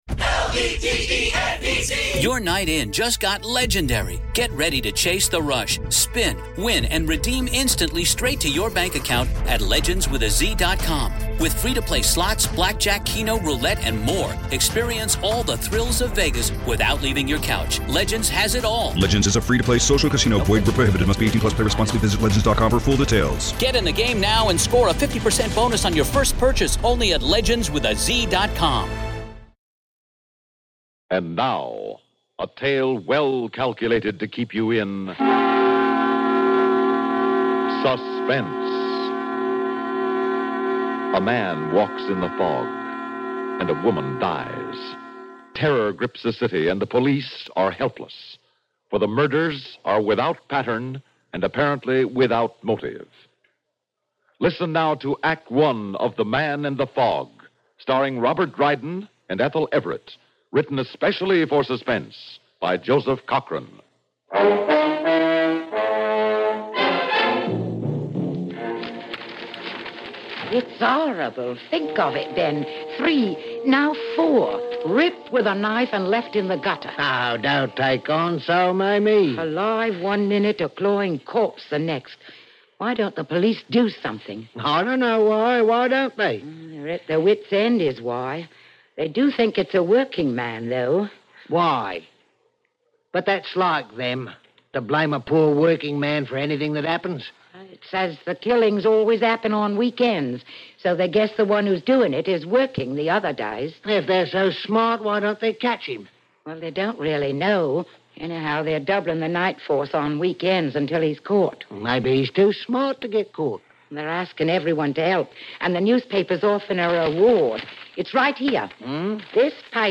On this week's episode of the Old Time Radiocast, we present you with two stories from the classic radio program Suspense.